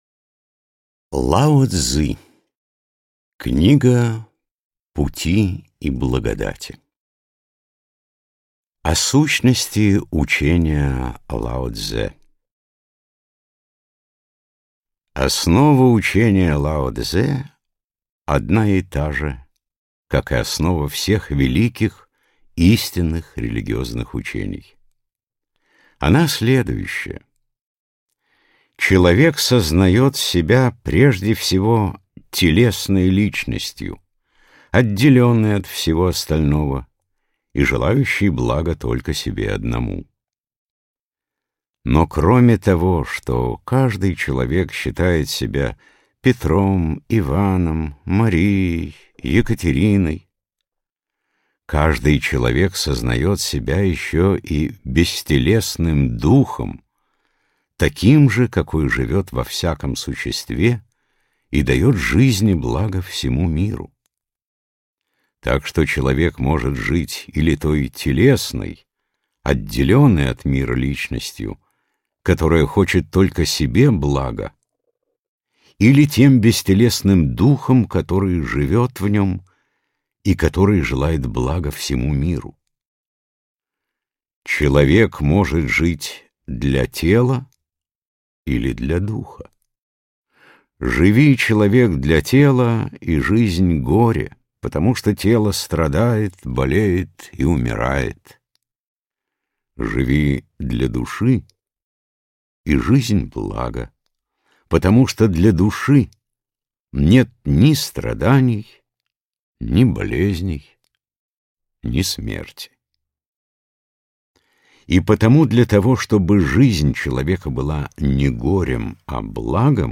Аудиокнига Книга пути и благодати (Дао дэ Цзин) | Библиотека аудиокниг